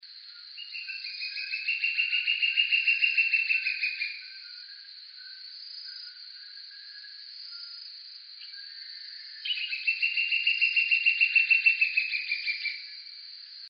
Trepador Oscuro (Dendrocolaptes platyrostris)
Nombre en inglés: Planalto Woodcreeper
Fase de la vida: Adulto
Localidad o área protegida: Reserva Privada y Ecolodge Surucuá
Condición: Silvestre
Certeza: Vocalización Grabada
TREPADOR-OSCURO.MP3